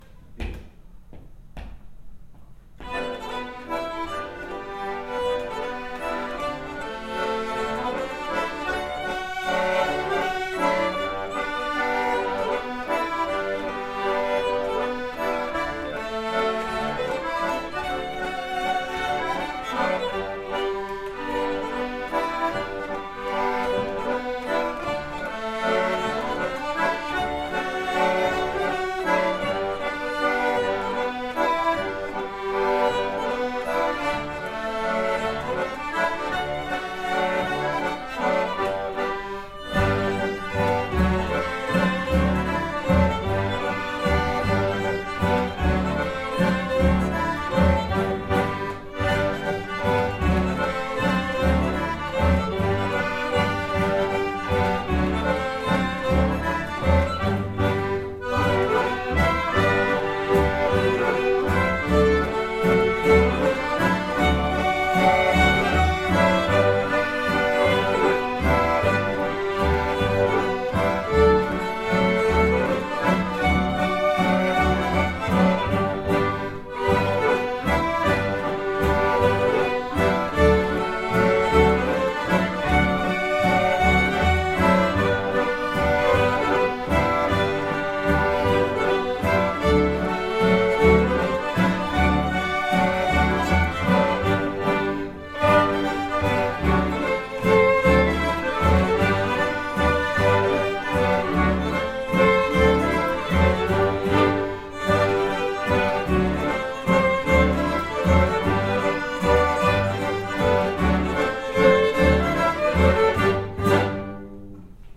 Nauhoitimme kilpailukappaleemme viimeisessä harjoituksessa ennen kilpailua.